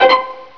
PLUCK_C.WAV